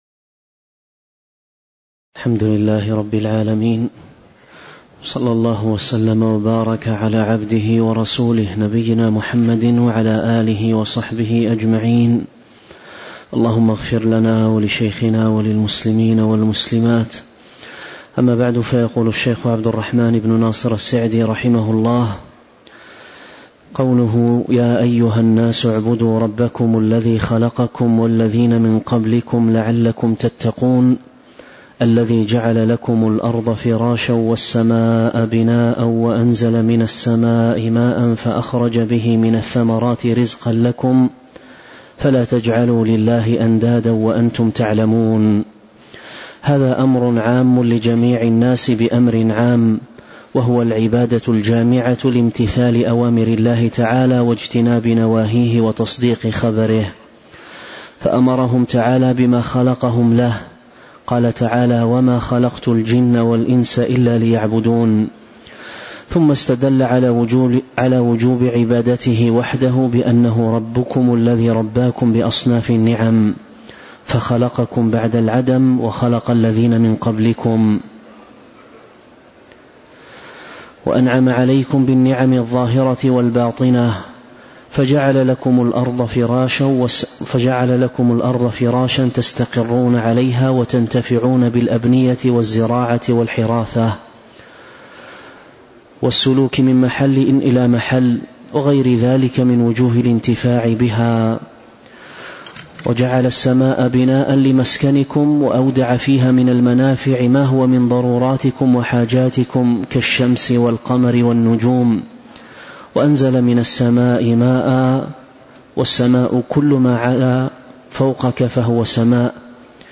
تاريخ النشر ٢٩ ربيع الأول ١٤٤٦ هـ المكان: المسجد النبوي الشيخ